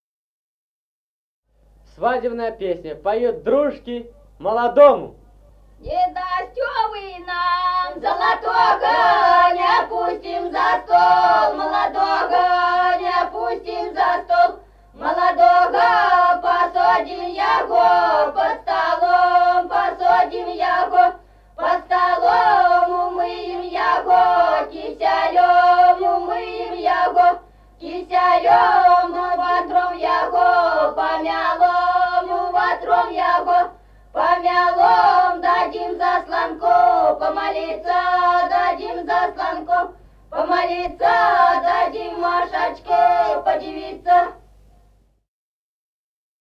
Народные песни Стародубского района «Не дасьтё вы нам», свадебная, дру́жки поют молодому.
(подголосник)
(запев).
с. Мишковка.